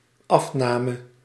Ääntäminen
IPA: /af.na.mə/